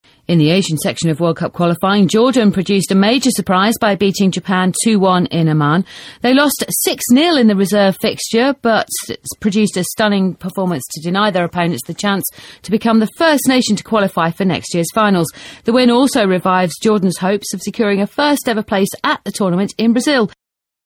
【英音模仿秀】日本无缘提前出线 听力文件下载—在线英语听力室